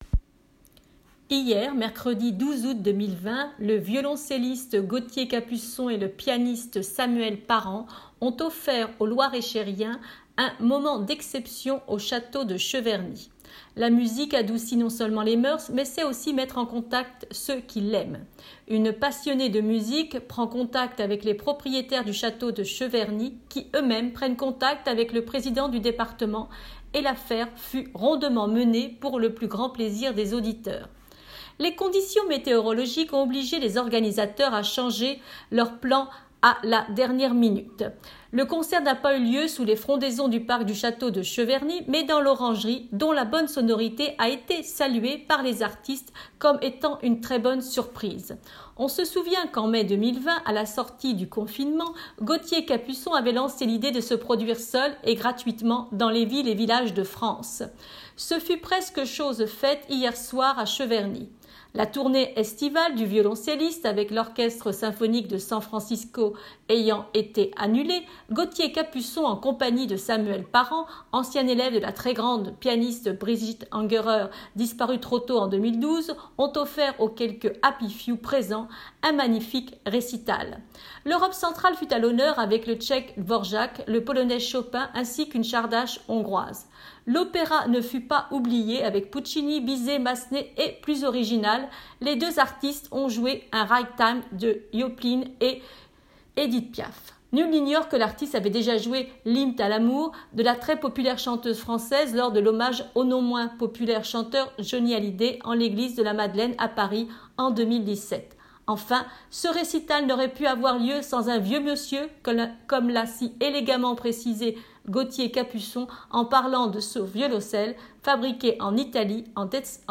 récital
violoncelliste
L'opéra ne fut pas oublié avec Puccini, Bizet, Massenet et plus original, les deux artistes ont joué un ragtime de Joplin et Edit Piaf.